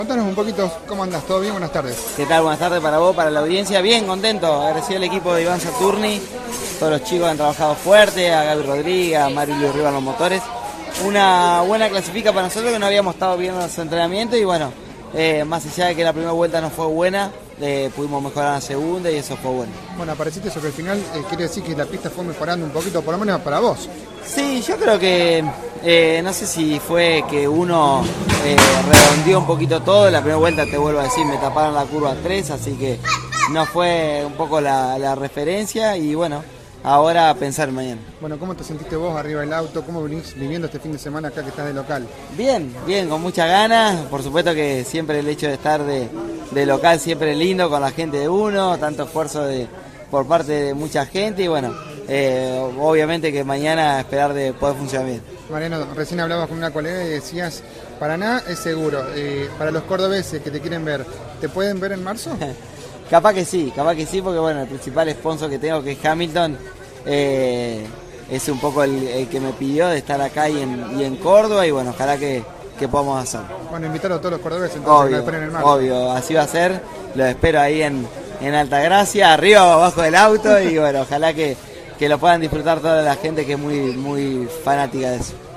La cita paranaense del TN tuvo a CÓRDOBA COMPETICIÓN cubriendo las acciones del evento y, allí, el «Zorro» pasó por nuestros micrófonos. Así entonces, Werner habló sobre el arranque del año y, además, confirmó que estará en el segundo compromiso de la temporada en el «Autódromo Oscar Cabalén»